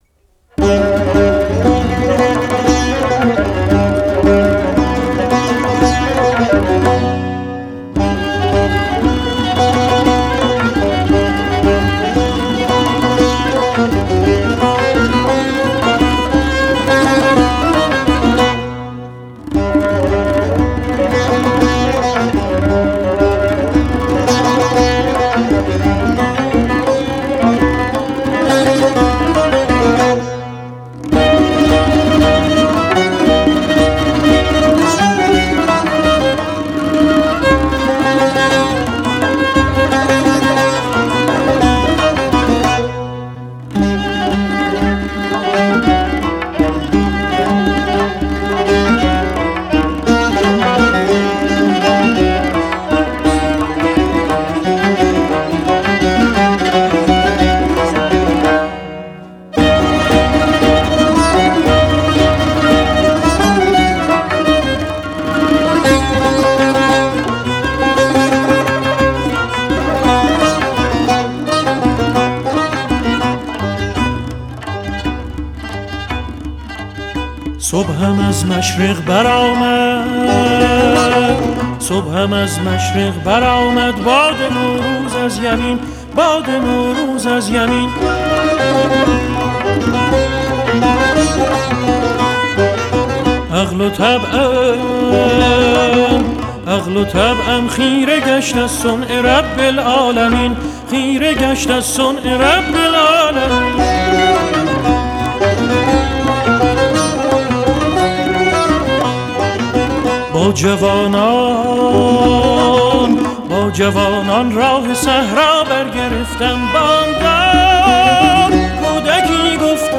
Tasnif Chahargah